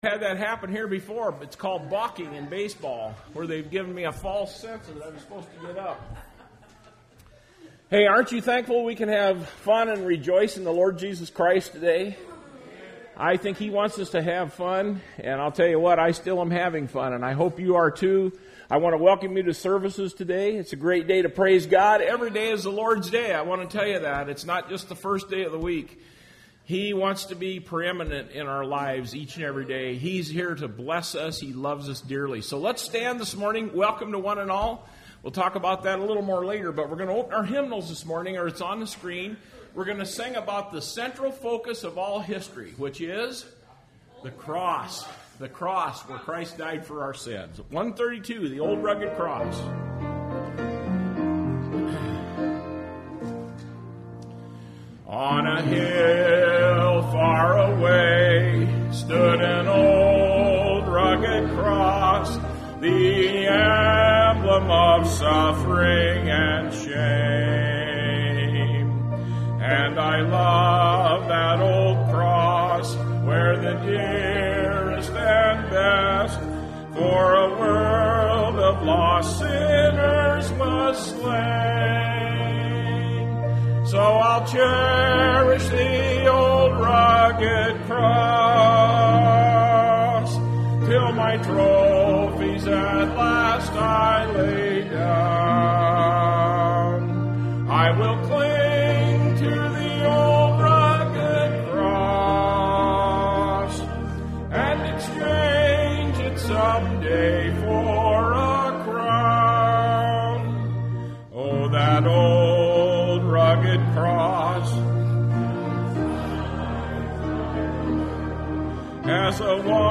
Service Type: Sunday Morning Service Topics: Christian Living , Doctrine , Spiritual Growth